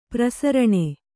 ♪ prasaraṇe